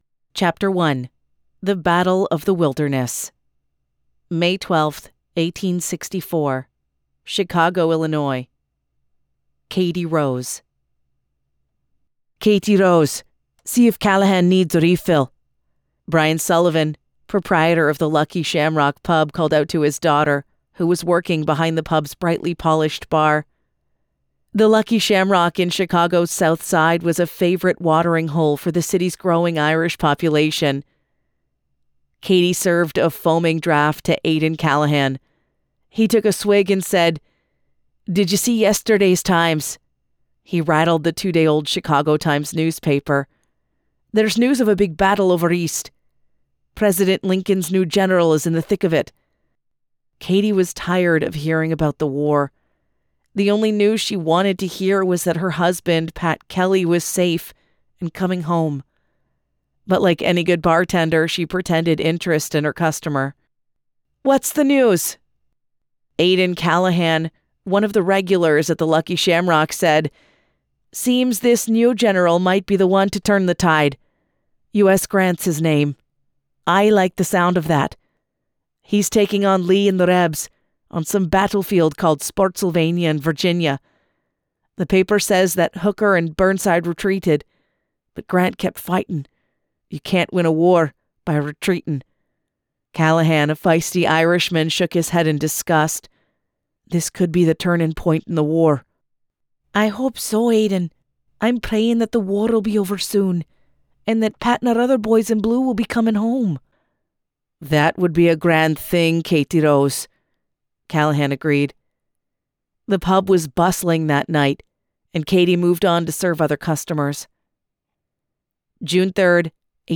Brought vividly to life in this immersive audiobook, this story captures the raw beauty and danger of America’s western plains.